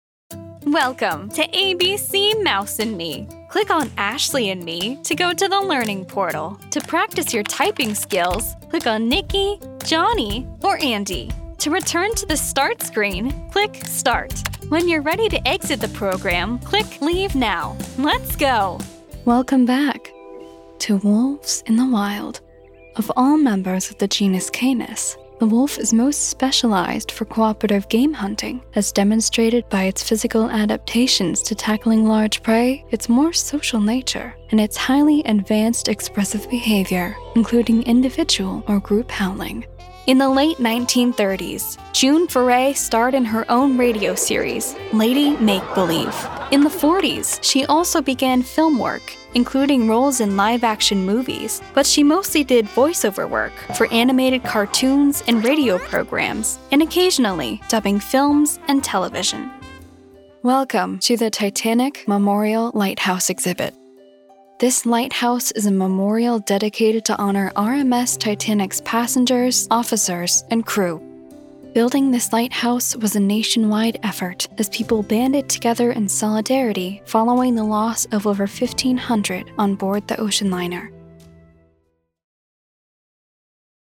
Child, Teenager, Young Adult, Adult
Has Own Studio
NARRATION 😎
husky